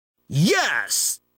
PLAY Captain Falcon UUUAAGGHHH scream
Play, download and share Captain Falcon original sound button!!!!